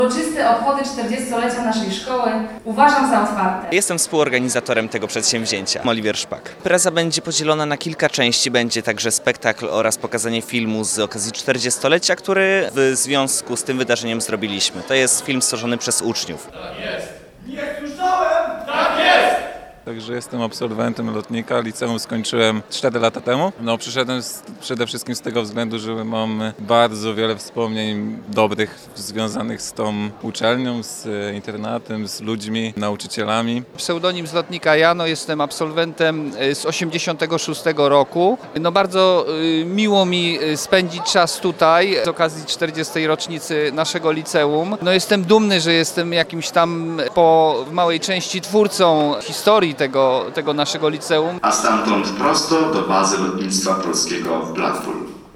Mszą świętą i uroczystą galą rozpoczęły się obchody 40-lecia szkoły. W Sali Kolumnowej Urzędu Marszałkowskiego zgromadziło się ponad 300 osób.